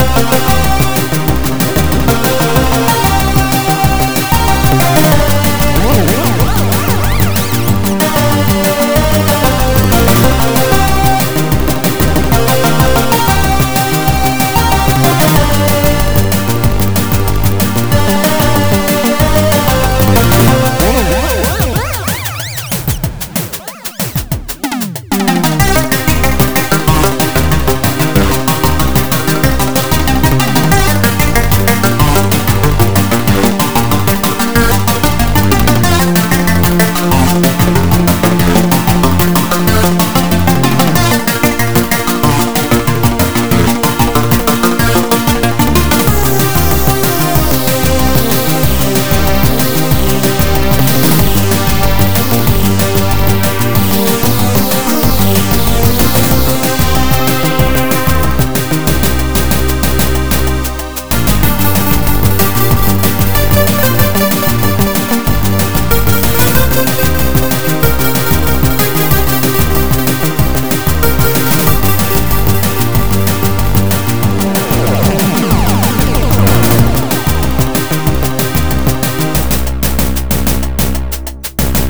(Port) is a converted track from 3-4 to 9 channels